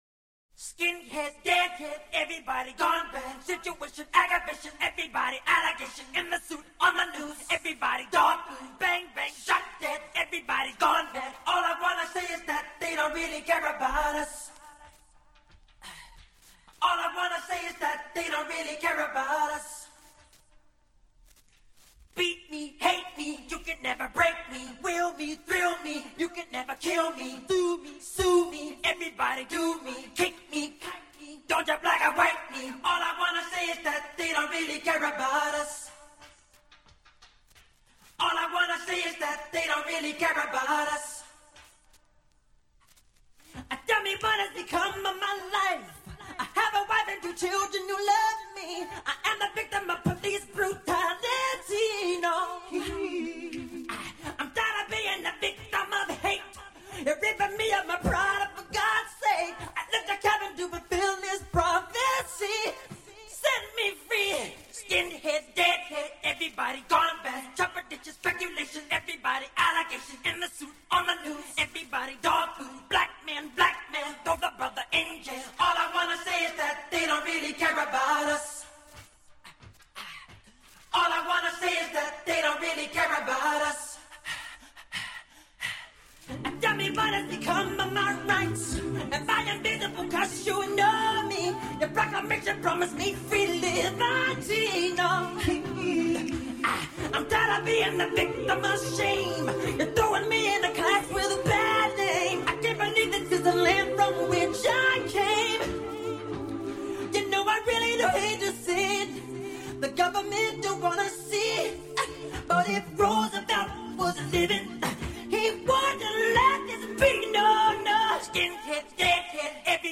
Скачать Зарубежные акапеллы [150]